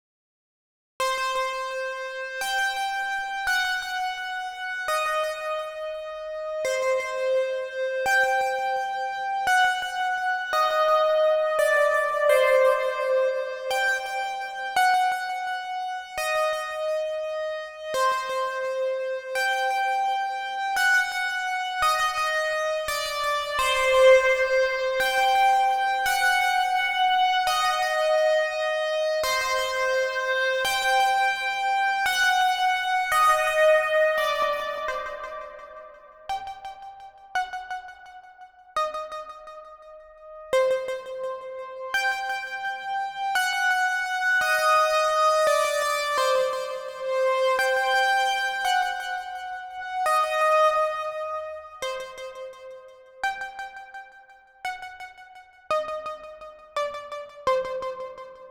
Brubra-170-C-Minor-.wav